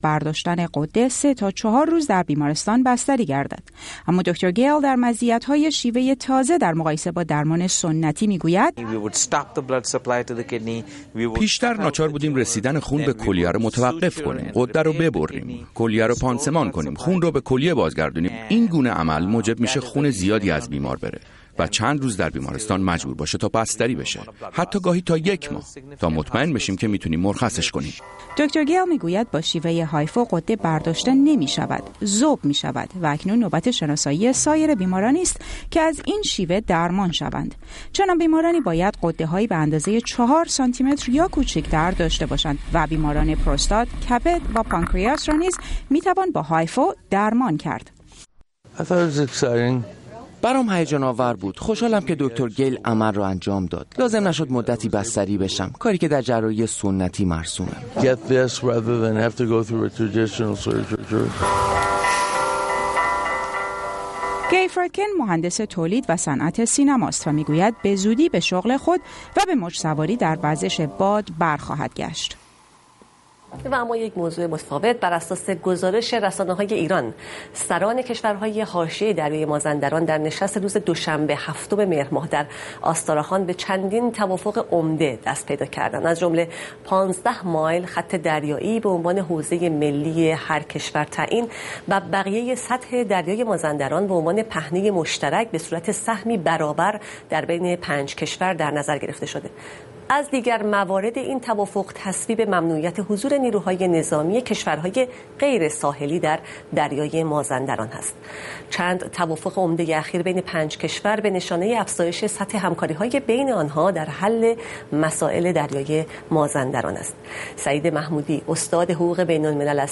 زندگی چهره های نامدار و ماندگار در اين برنامه در قالب يک مستند نيم ساعته به تصوير کشيده می شود. در اين برنامه هنرمندان، پژوهشگران، استادان دانشگاه، فعالان اجتماعی و ديگر شخصيتهای نام آشنا ما را با زندگی پر بار خود آشنا می کنند.